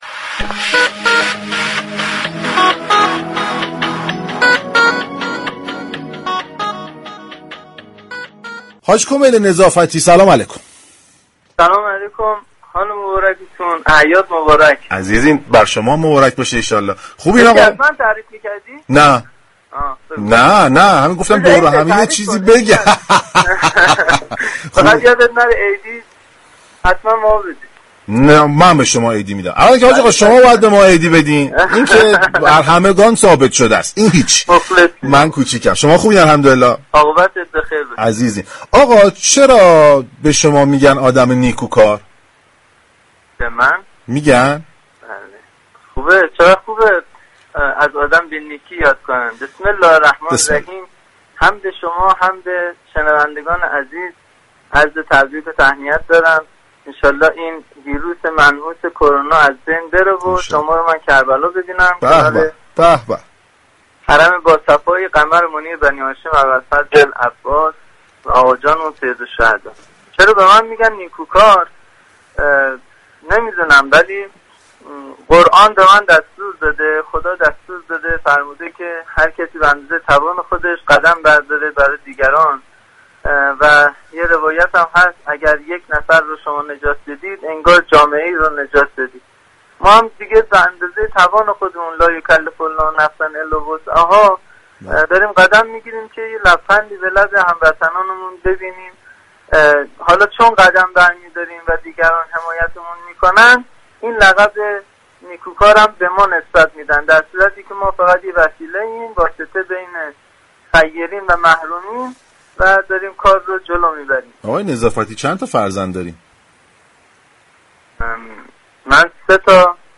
در گفتگو با تهران من